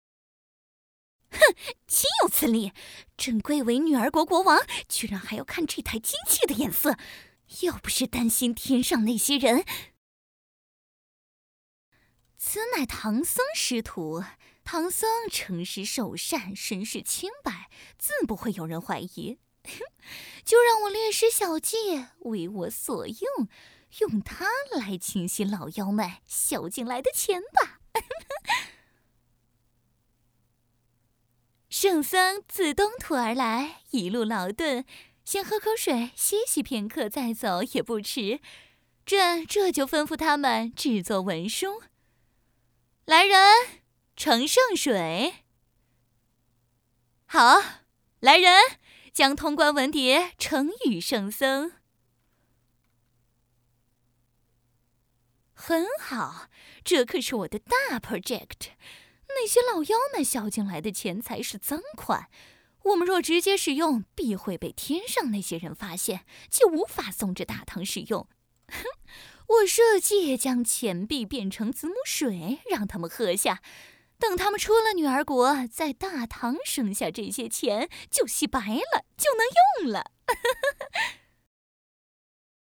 • 39专业女声7
影视角色【妖艳】